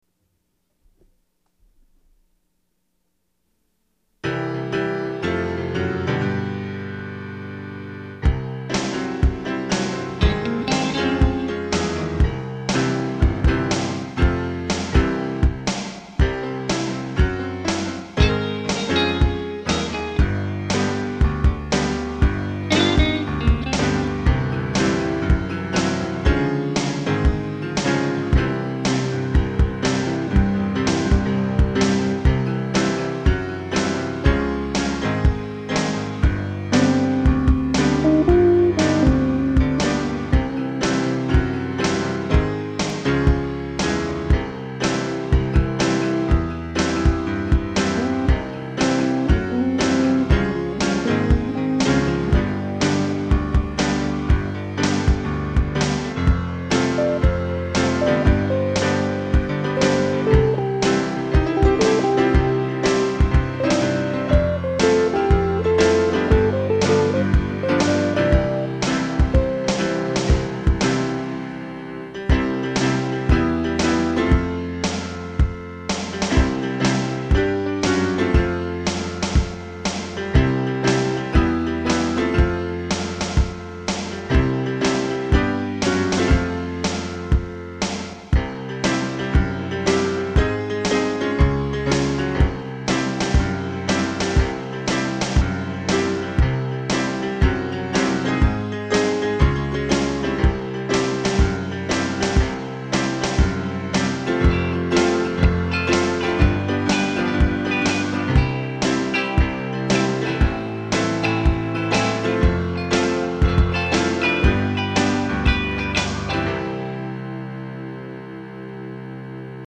I decided to make the song an instrumental and center it around piano, since words take a long time to write and piano's what I'm used to. The theme is that of some simple blues sounds interspersed between a bizarre chord progression.
There's a bit of electric guitar which is just little riffs that survived many deletings. Then there's a super basic drum track to fill things out.